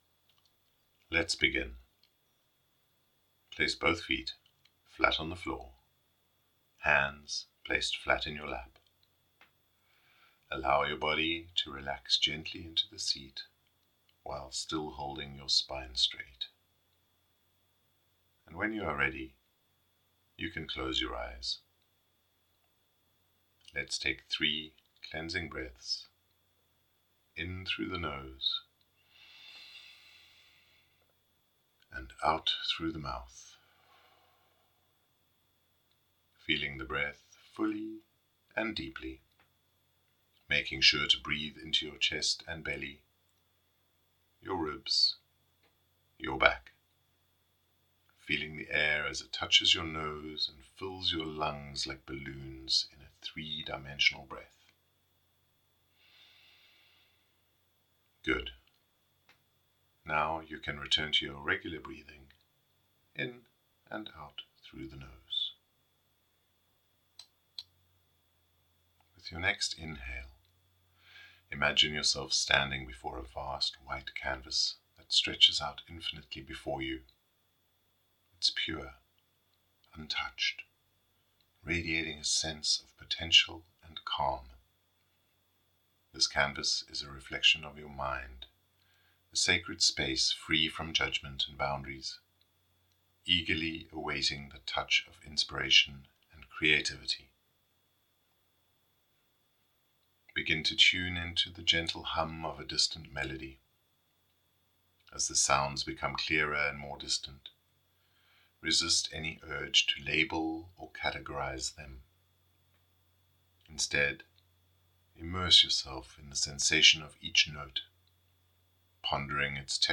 Sing my own song Meditation
WS09-meditation-Sing-my-own-song.mp3